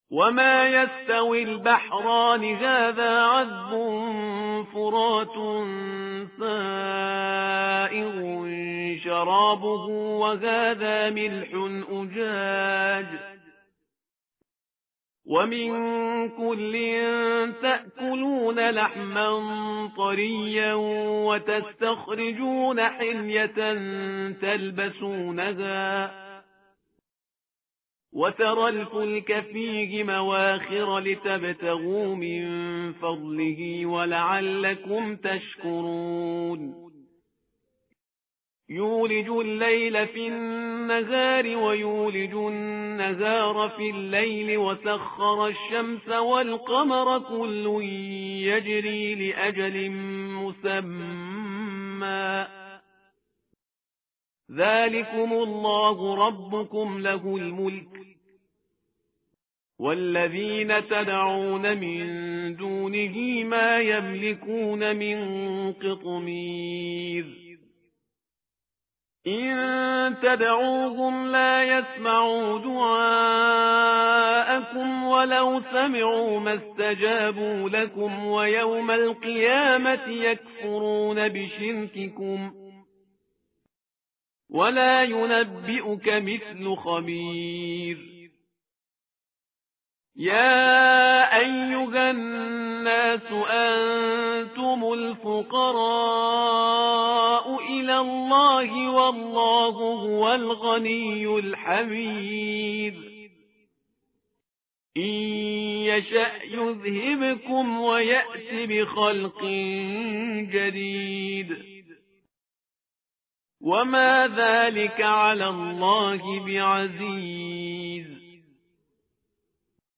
tartil_parhizgar_page_436.mp3